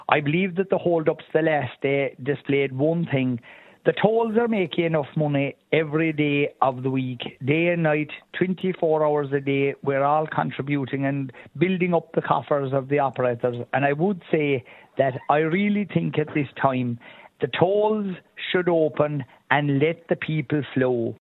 Junior Minister and Kerry TD Michael Healy-Rae doesn’t want a repeat of that: